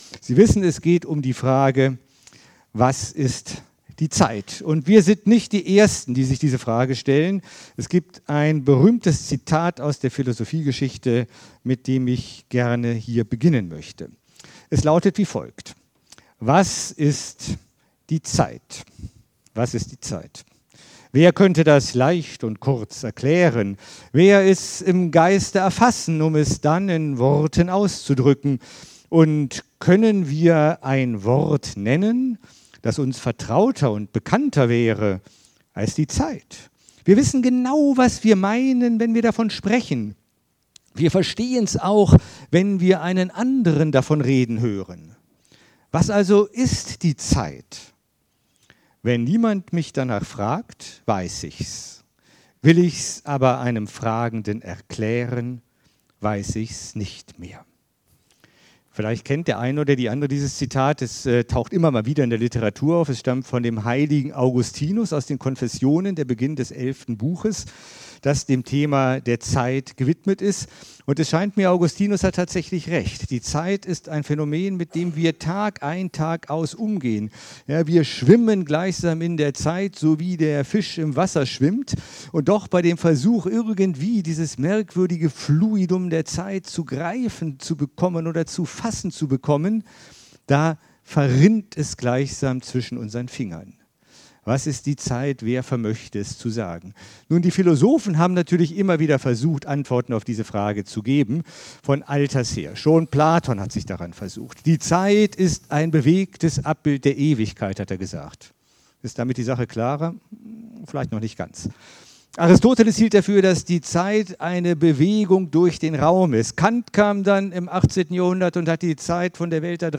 Impuls
als Einführung in die Veranstaltung „Nacht der Philosophie“ über die ZEIT in Fulda im November 2024.